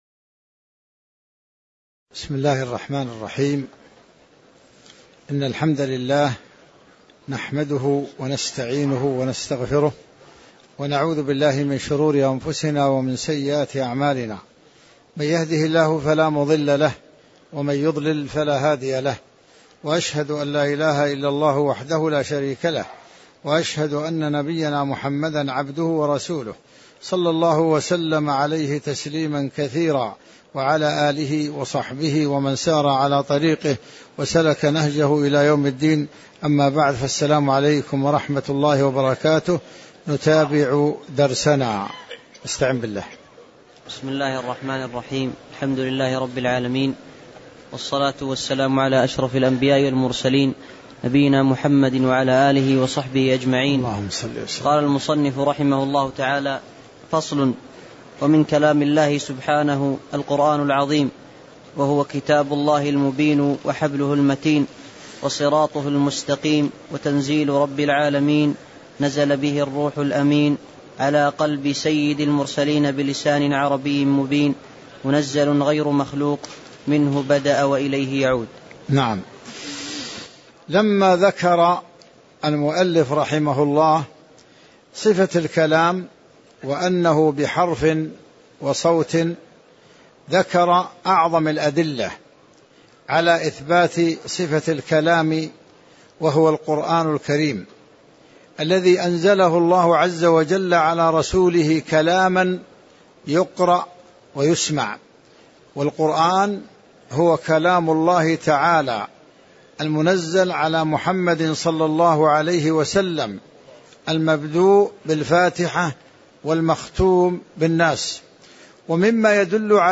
تاريخ النشر ٦ جمادى الآخرة ١٤٣٧ هـ المكان: المسجد النبوي الشيخ